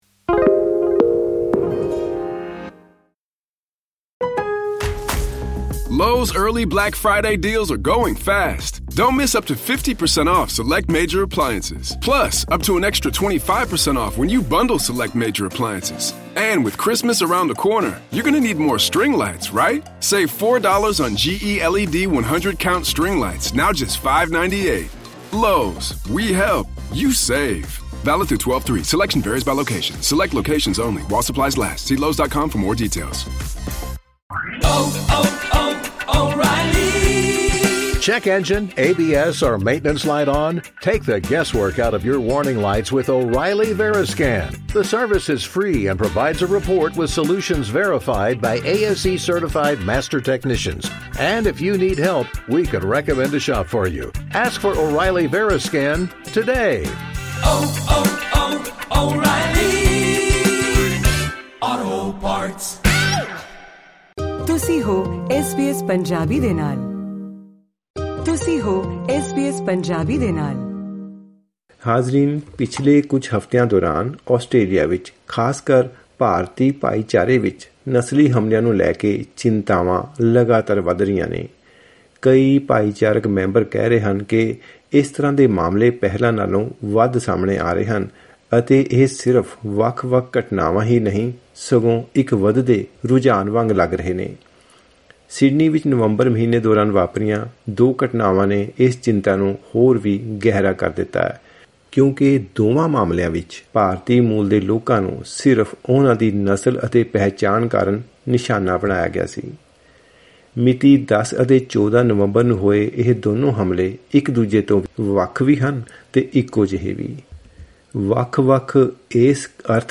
ਪੀੜਤ ਵਿਅਕਤੀ ਨਾਲ ਕੀਤੀ ਗੱਲਬਾਤ ਅਤੇ ਨਾਲ ਹੀ ਲੋਕਲ ਐਮ ਪੀ, ਪ੍ਰੀਮੀਅਰ ਅਤੇ ਭਾਈਚਾਰਕ ਸੰਸਥਾਵਾਂ ਦੇ ਨੇਤਾਵਾਂ ਵੱਲੋਂ ਇਹਨਾਂ ਮਾਮਲਿਆਂ ਉੱਤੇ ਕੀਤੇ ਪ੍ਰਤੀਕਰਮ ਲਈ ਸੁਣੋ ਇਹ ਐਕਸਪਲੇਨਰ....